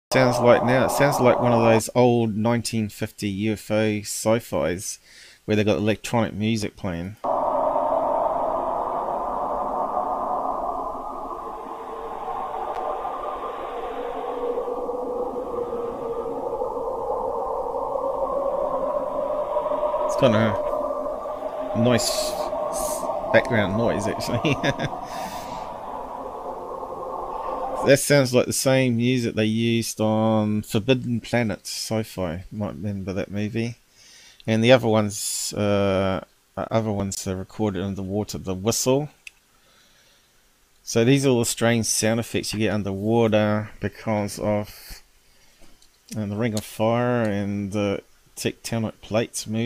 Weird under water sounds are most like steam vents from underwater volcanoes plates not craft